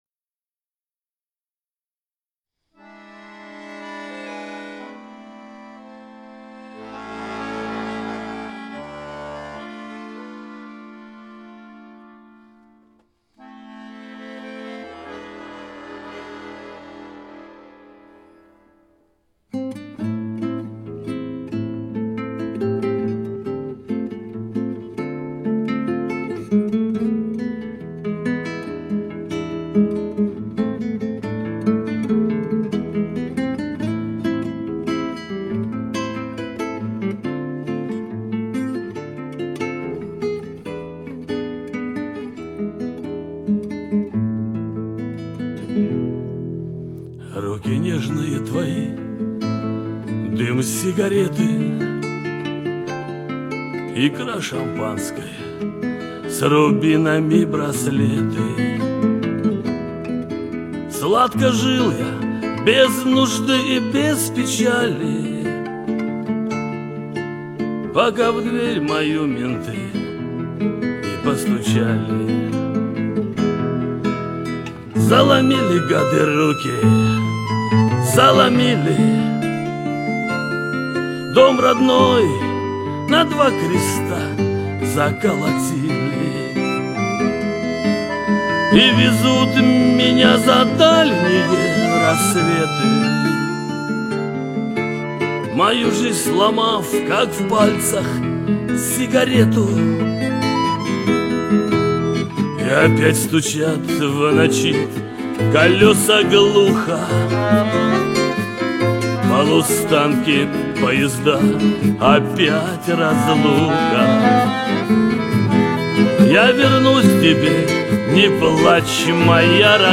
Небольшая подборка прекрасного шансонье...